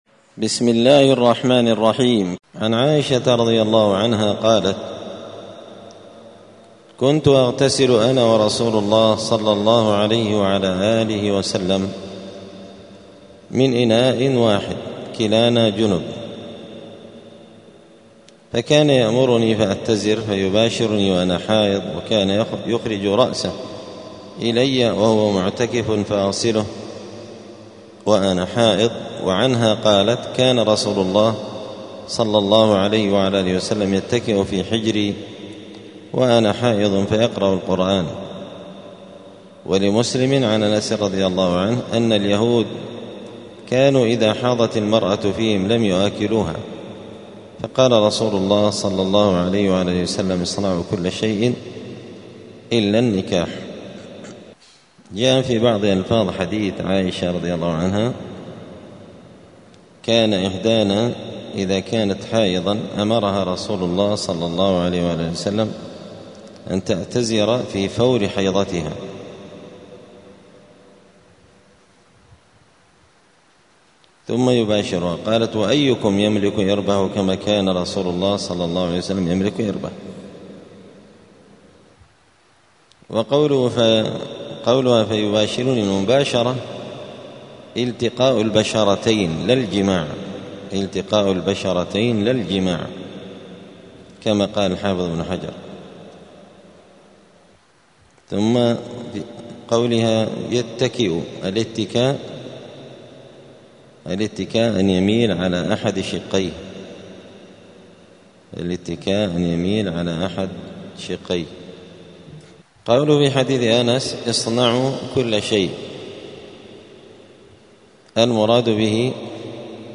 دار الحديث السلفية بمسجد الفرقان قشن المهرة اليمن
*الدرس الرابع والتسعون [94] {باب الحيض حكم مؤاكلة ومشاربة ومضاجعة ومجامعة الحائض}*